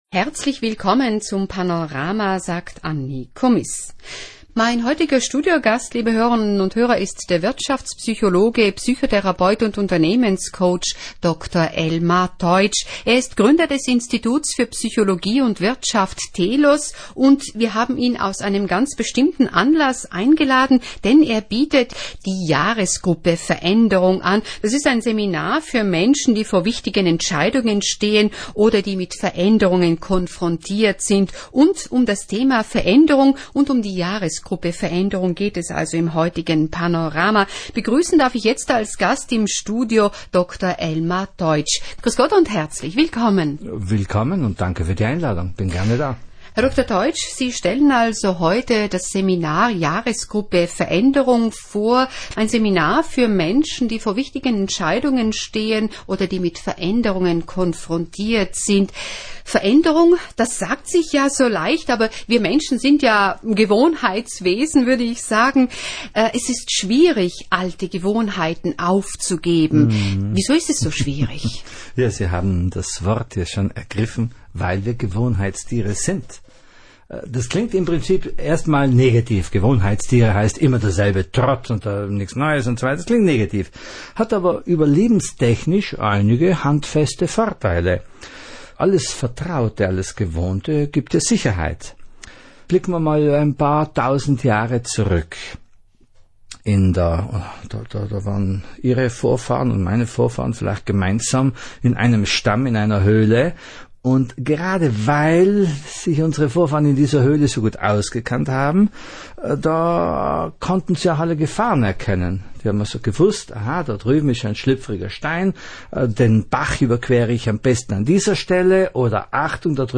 Komprimierte WEB-Version, Sprache und Musik, Ausschnitte, 35 Minuten, mp3,9 MB